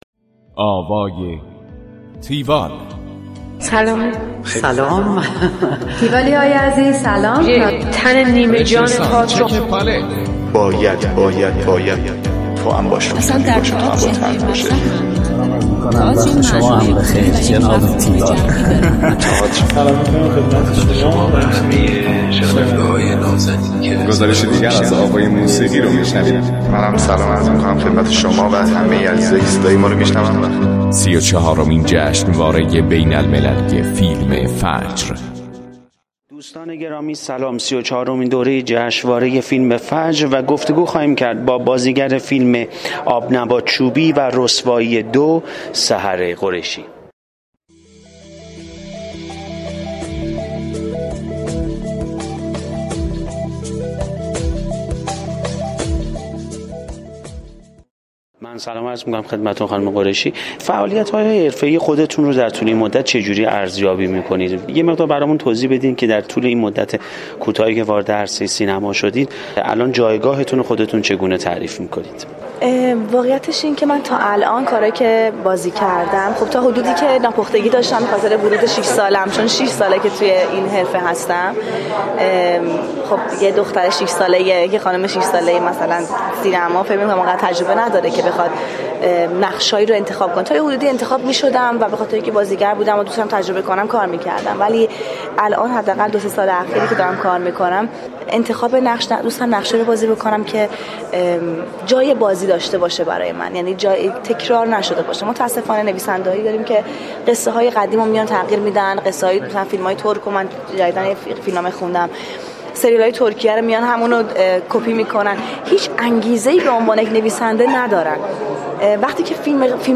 گفتگوی تیوال با سحر قریشی
tiwall-interview-saharghoreishi.mp3